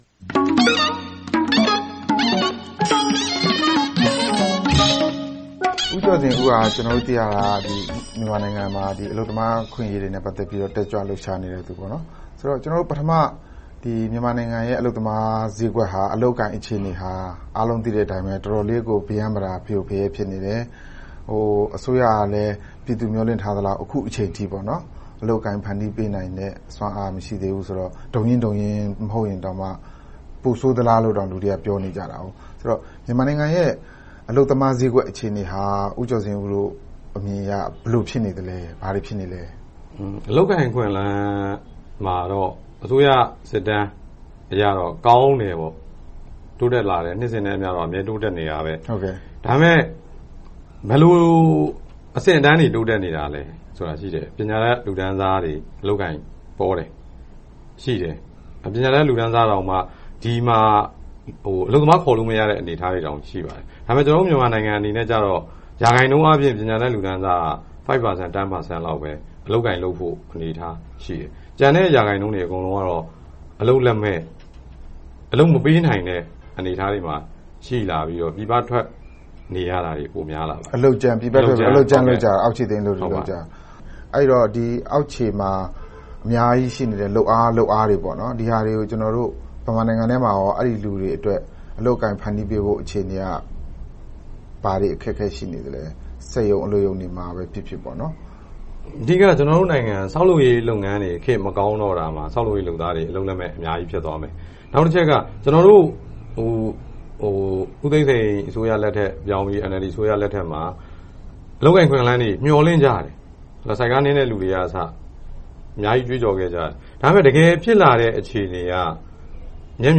တွေ့ဆုံမေးမြန်းထားပါတယ်။